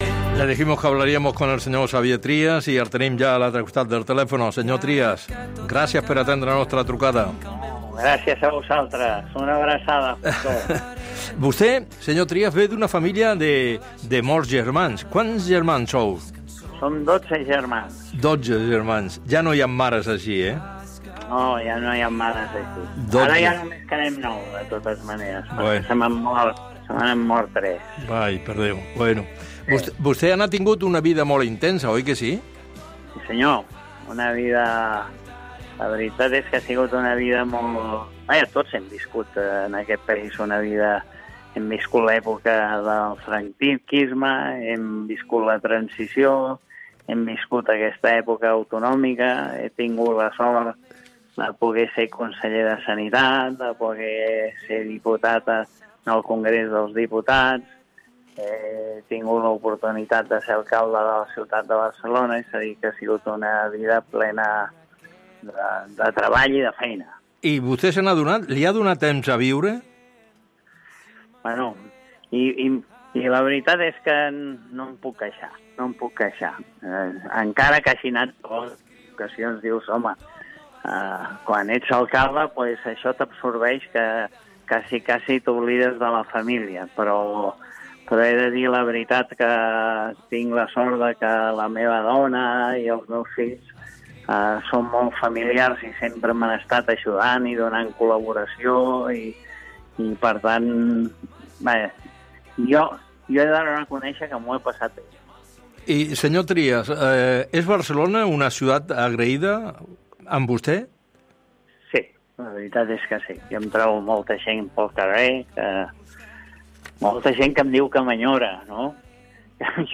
Entrevista a Xavier Trias
Justo Molinero ha entrevistado al ex-alcalde de Barcelona, Xavier Trias.
ent-tlf-xavier-trias-2-julio.mp3